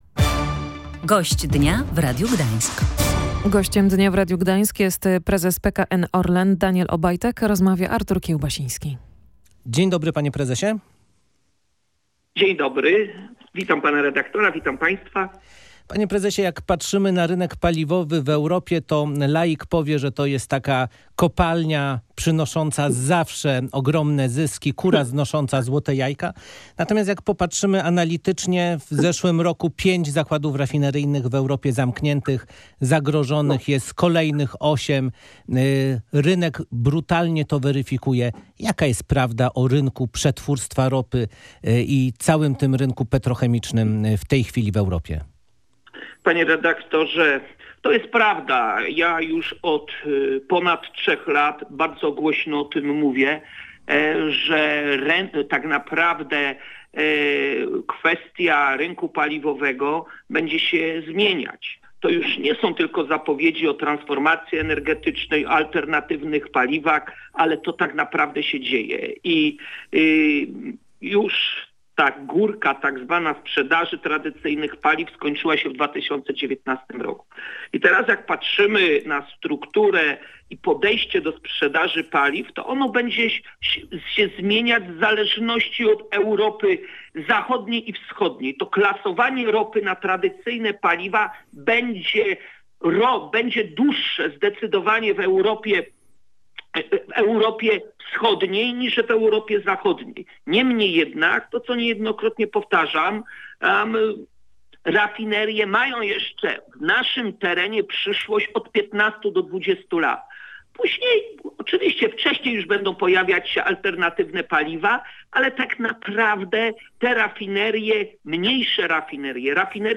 Nawet 19 milionów klientów będzie obsługiwał multikoncern, który powstanie z połączenia Orlenu, Lotosu, Energi, PGNiG i Ruchu – mówił w Radiu Gdańsk prezes PKN Orlen Daniel Obajtek.